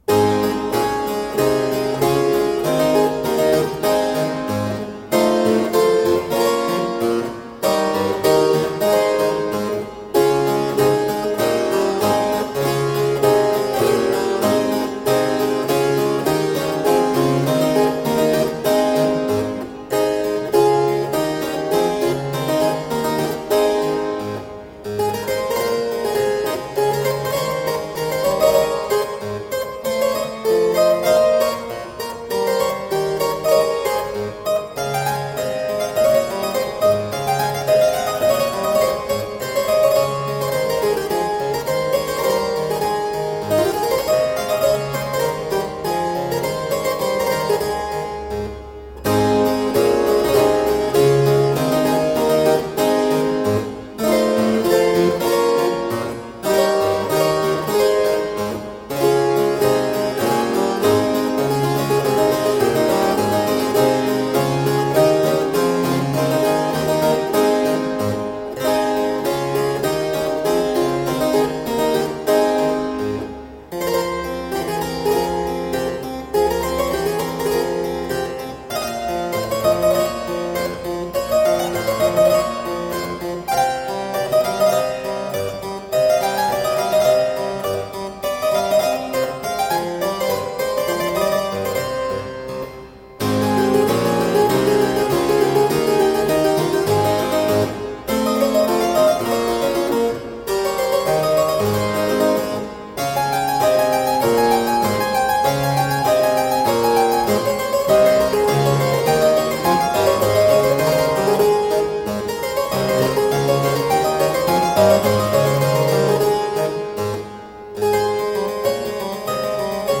Solo harpsichord music.
Classical, Baroque, Instrumental
Harpsichord